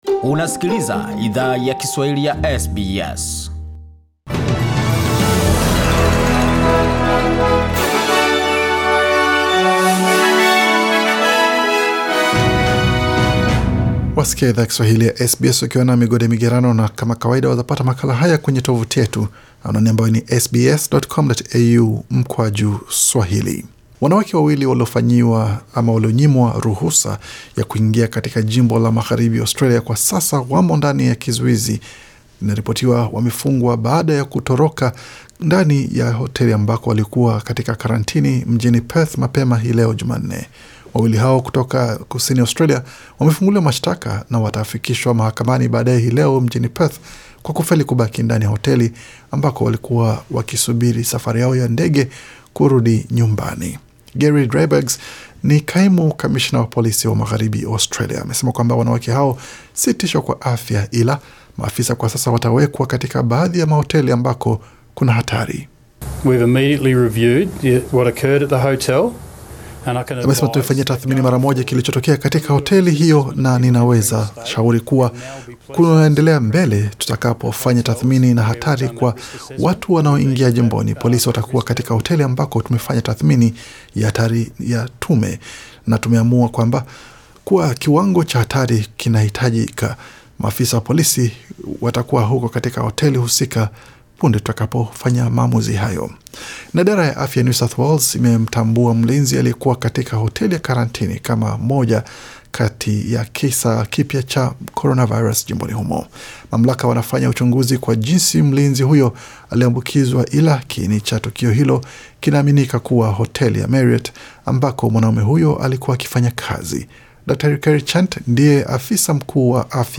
Taarifa ya habari 18 Agosti 2020